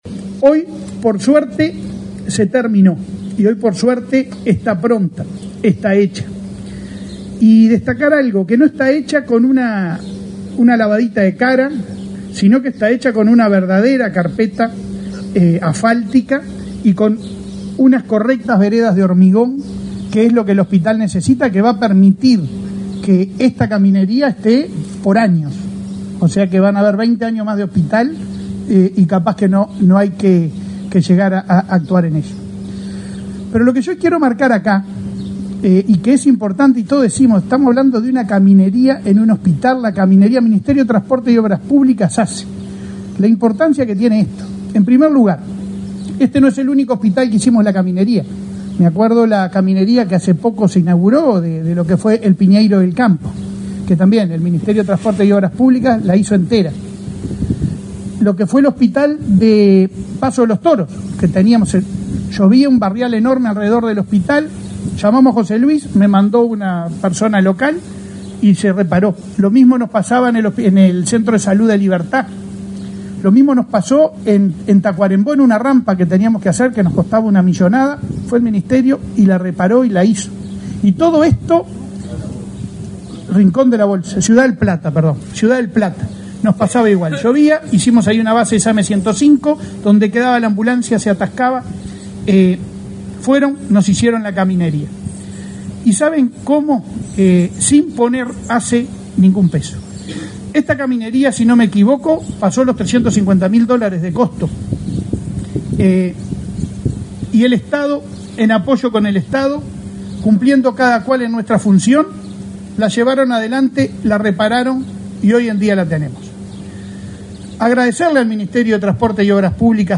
Palabras de autoridades en acto del hospital Pereira Rossell
Palabras de autoridades en acto del hospital Pereira Rossell 20/12/2023 Compartir Facebook X Copiar enlace WhatsApp LinkedIn El presidente de la Administración de los Servicios de Salud del Estado (ASSE), Leonardo Cipriani; el ministro de Transporte, José Luis Falero, y la presidenta de la República en ejercicio, Beatriz Argimón, participaron, este miércoles 20, en la inauguración de la caminería interna y las veredas del hospital Pereira Rossell.